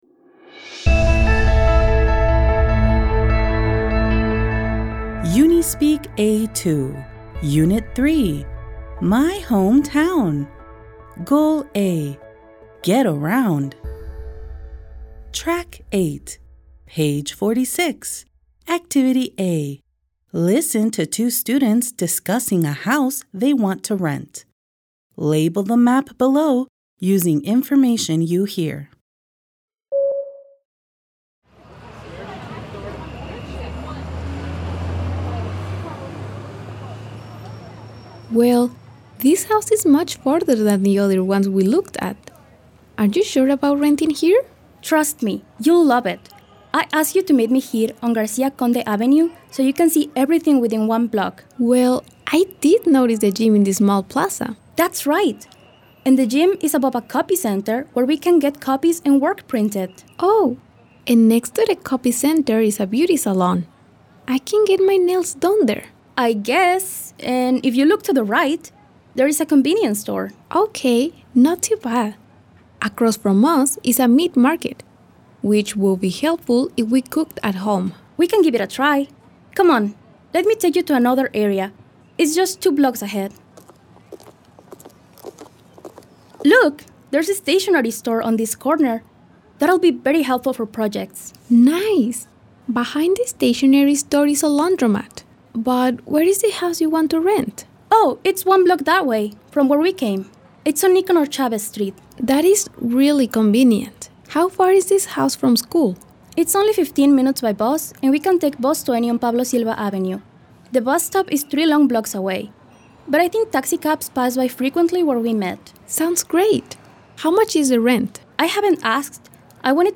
Listen to two students discussing a house they want to rent. Label the map below using information you hear.